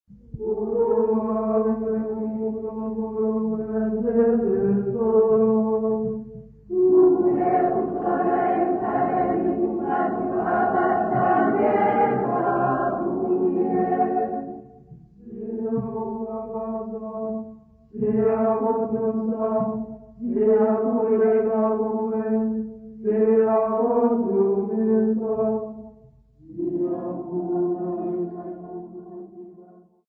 Church Choir
Hymns, Zulu South Africa
Choral music South Africa
field recordings
UUnaccompanied church praise hymn.